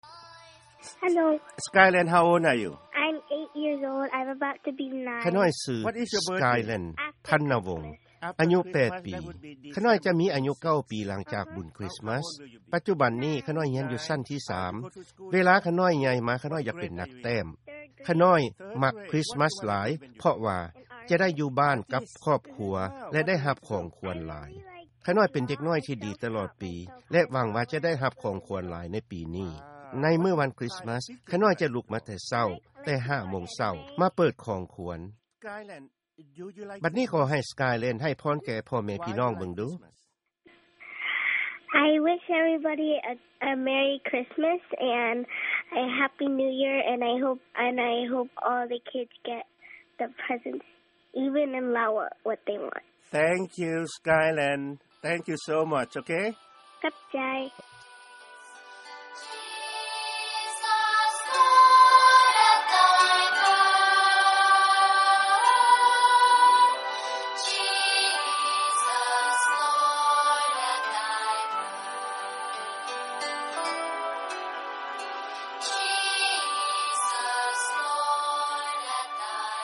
ການສຳພາດ